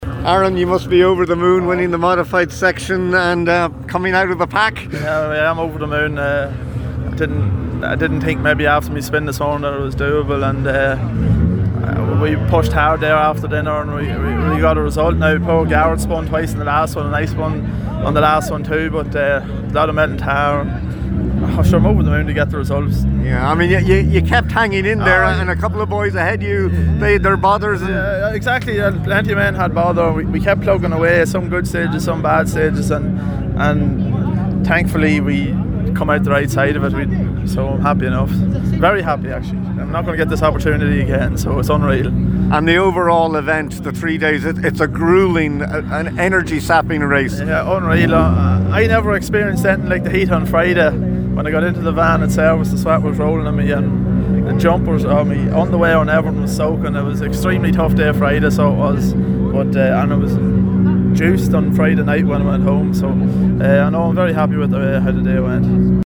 Top competitors react to enthralling Donegal International Rally – Finish-line chats